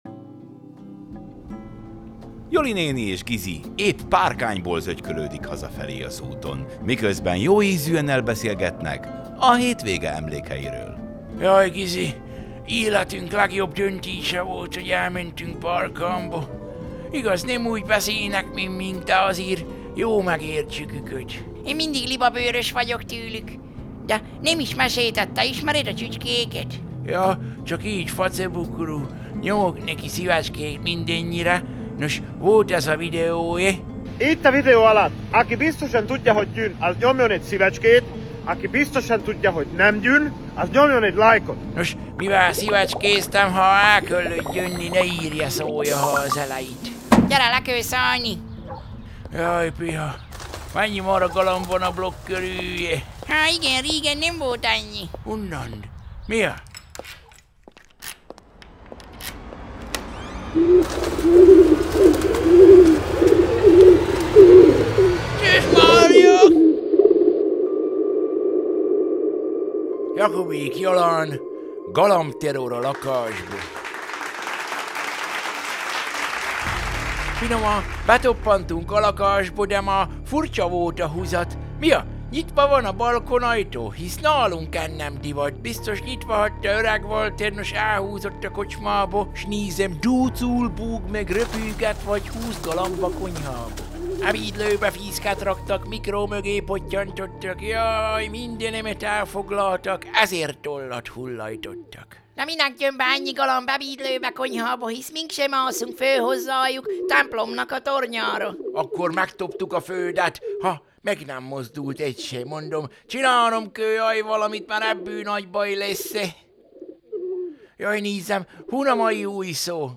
Szaval a jó öreg csallóközi: Galamb tërror a lakásbo